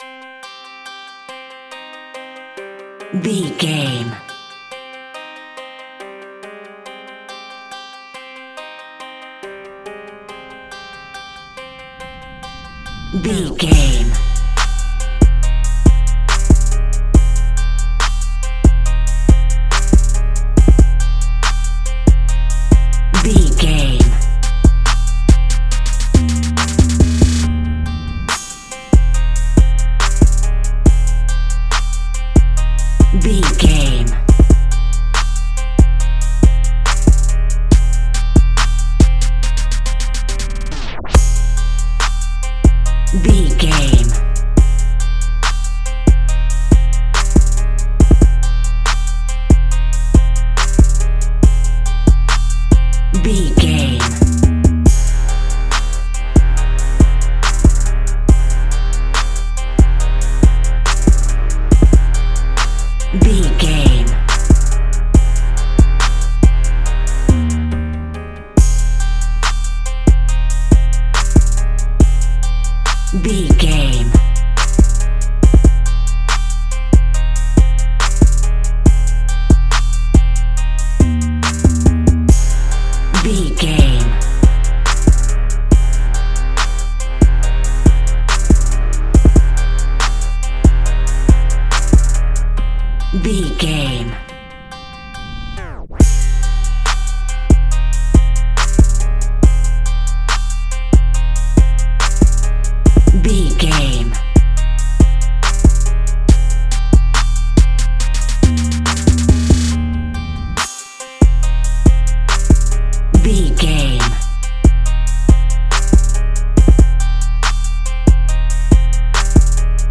Hip Hop Murder Theme.
Aeolian/Minor
synths
synth lead
synth bass
synth drums